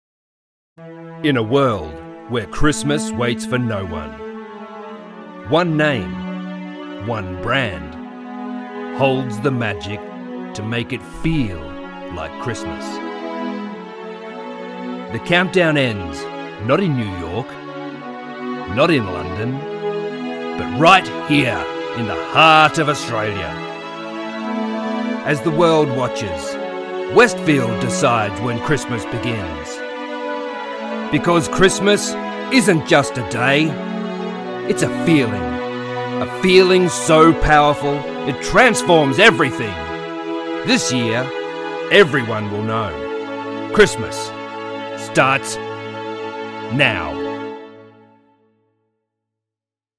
Male
My natural Australian accent is genuine and engaging.
Television Spots
Characterful Australian
0110Christmas_Countdown_commercial.mp3